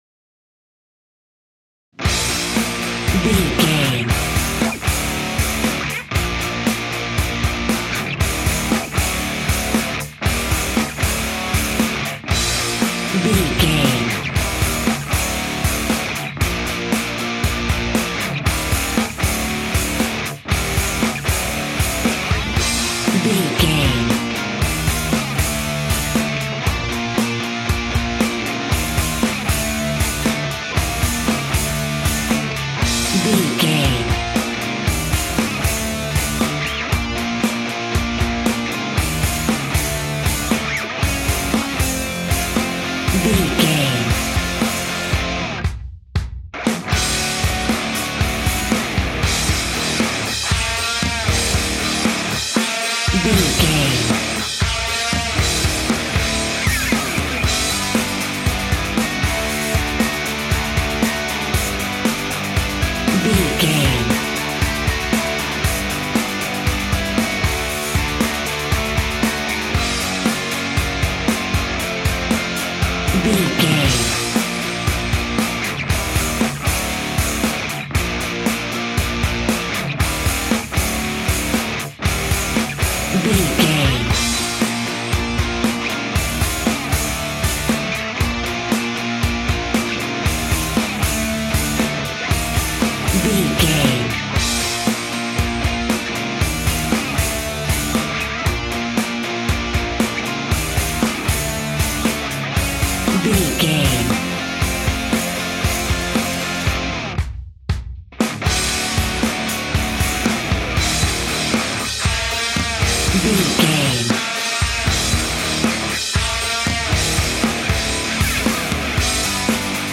Epic / Action
Ionian/Major
B♭
hard rock
heavy metal
blues rock
distortion
rock guitars
Rock Bass
Rock Drums
heavy drums
distorted guitars
hammond organ